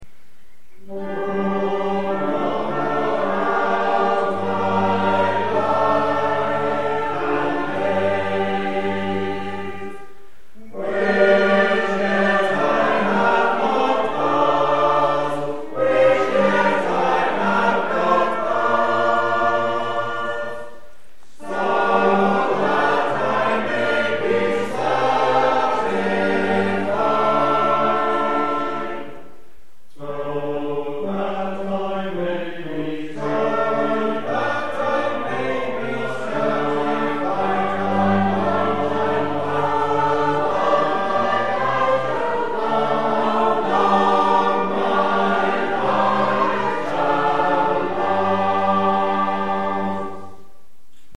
Music in the service:
Verse 5 - an unpublished setting by a local amateur composer, from a ms book used at St.Laurence's church, Catsfield, E.Sussex. Here is the first page.